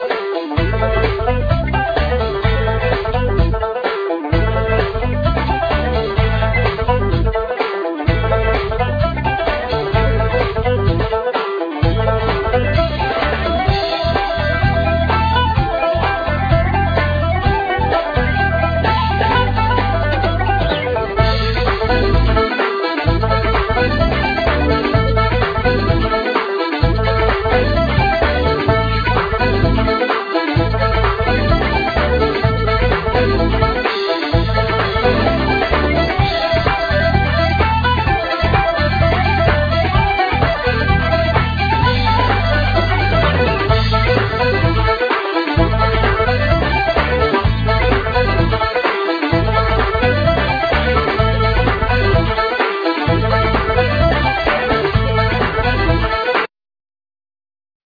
Flute,Tin & Low whistles,Bodhram
Guitar,Bocoder voice
Mandlin,Bouzoki,Banjo,Sitar,Percussions,Programming
El.bass
Drums
Accordion
Keyboards
Violin
Vocal,African percussions